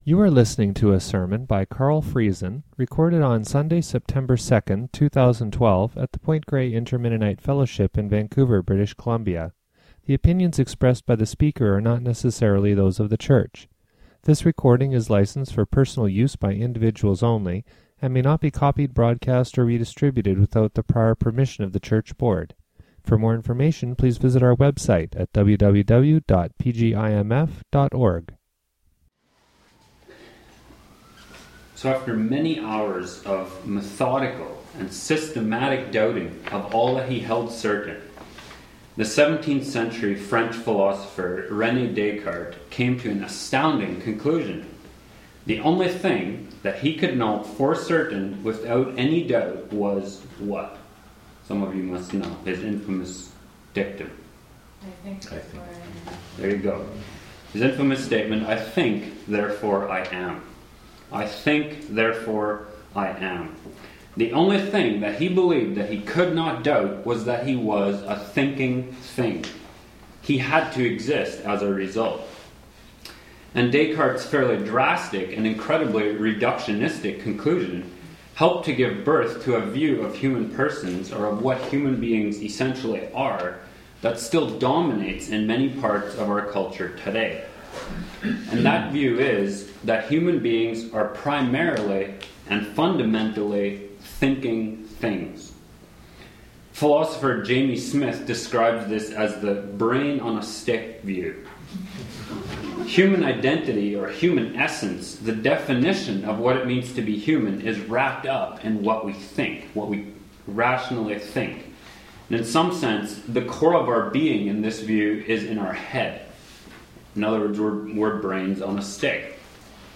Labels: PGIMF sermon discussion